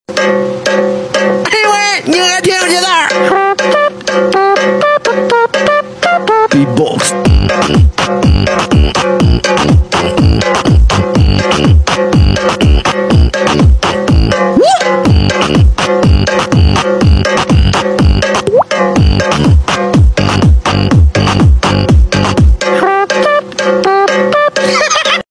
Beatbox Wash Basin X Neymar Sound Effects Free Download